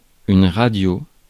Ääntäminen
France: IPA: /ʁa.djo/